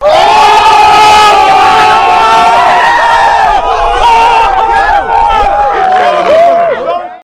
Звуки криков
2. Бурный крик толпы